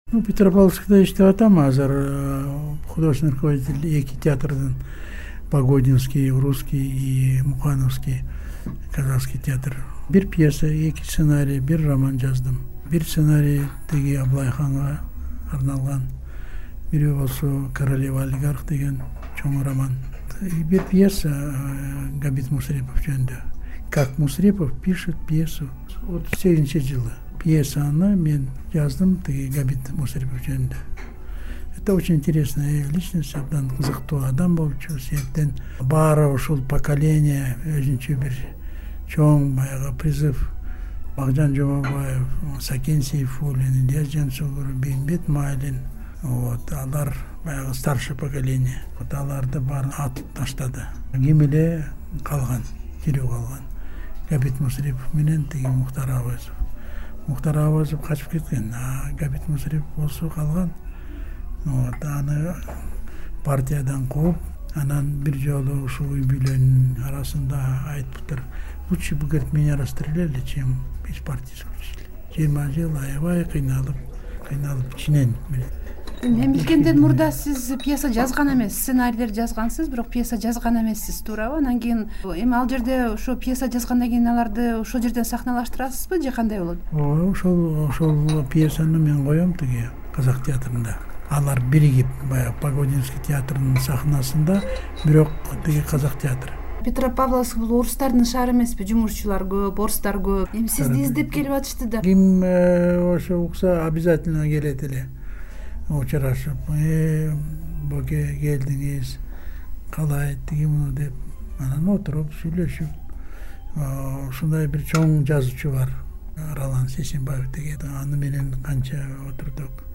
Режиссер Болот Шамшиев менен маек